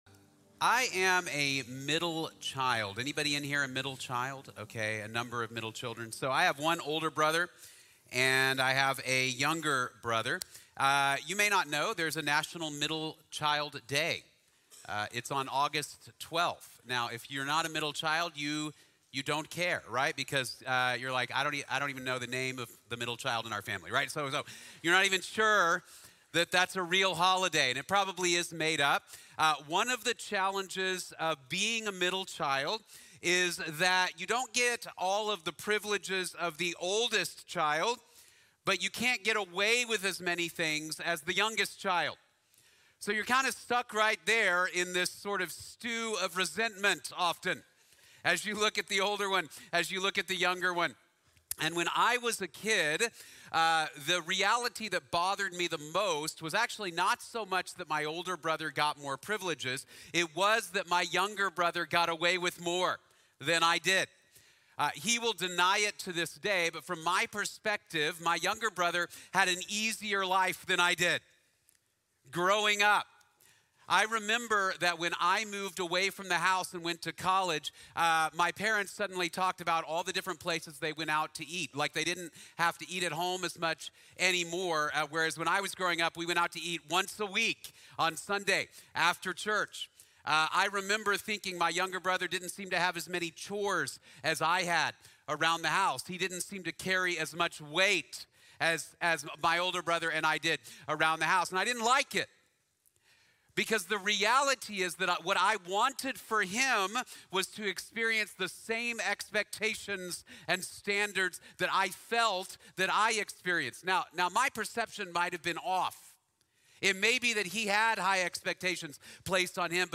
La Gracia Vale la Pelea | Sermón | Iglesia Bíblica de la Gracia